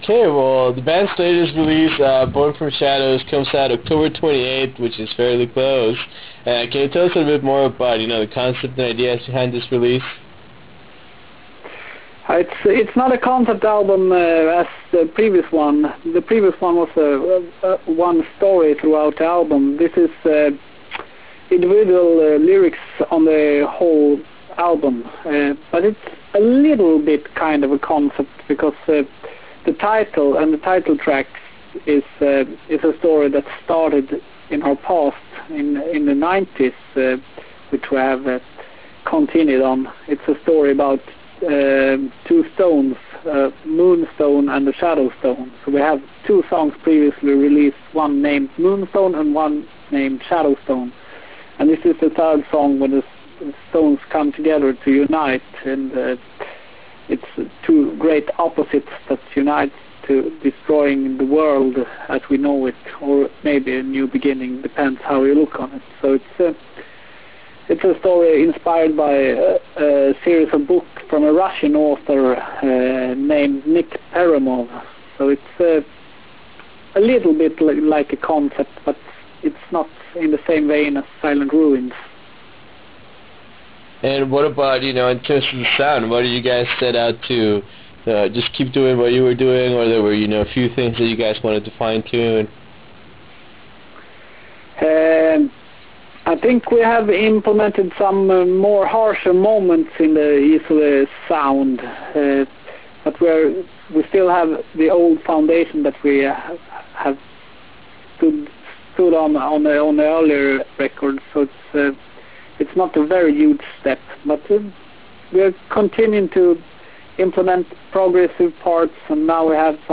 Interview with Isole's